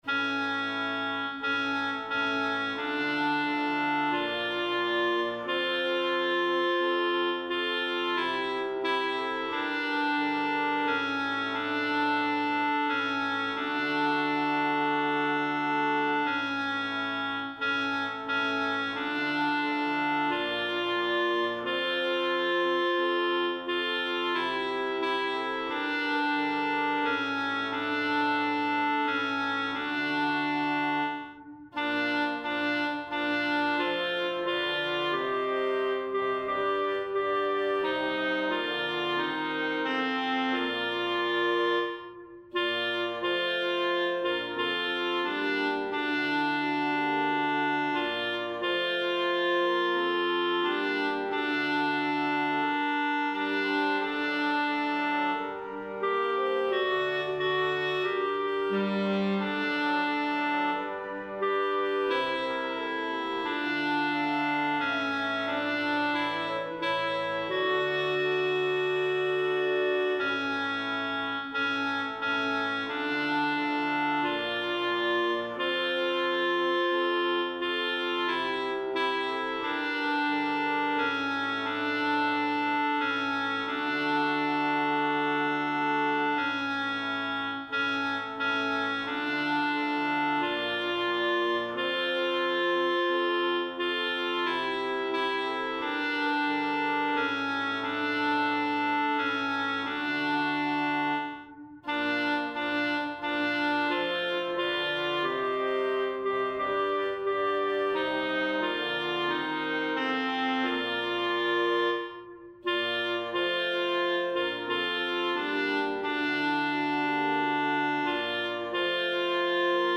Alto
Anthem